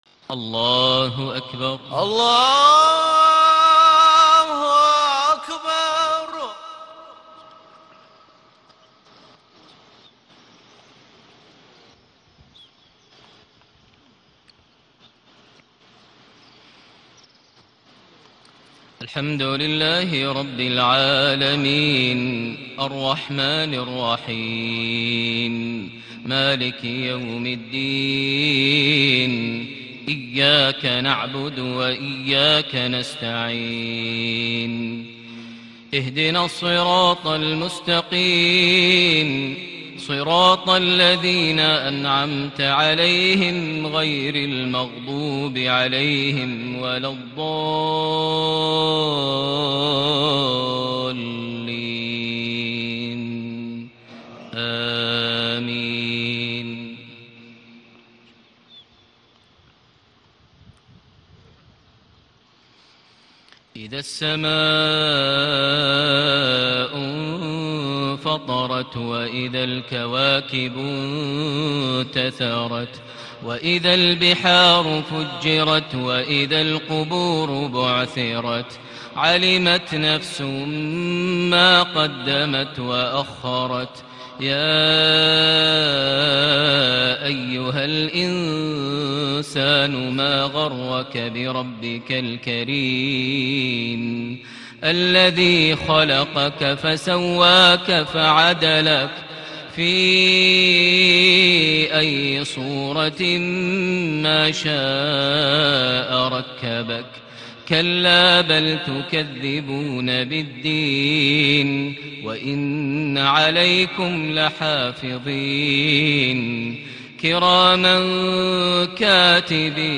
صلاة المغرب ٢٧ صفر ١٤٣٨هـ سورتي الإنفطار - الليل > 1438 هـ > الفروض - تلاوات ماهر المعيقلي